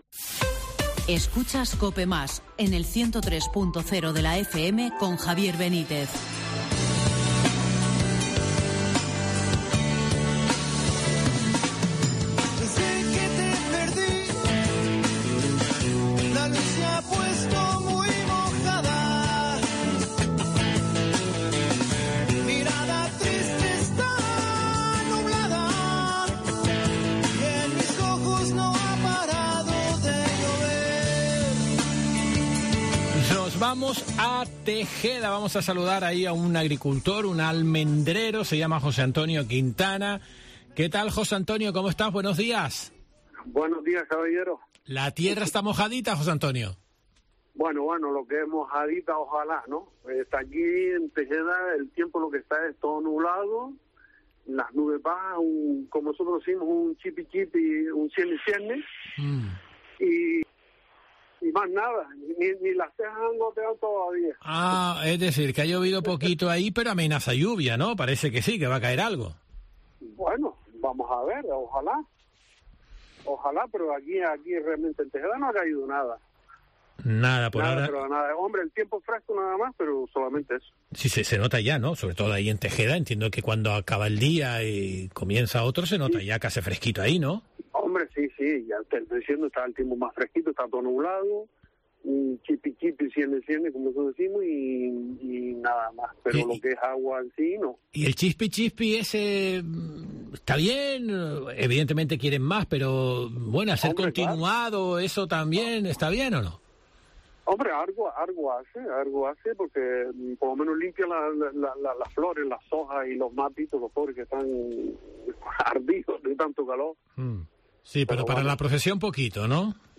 Por Herrera en COPE Gran Canaria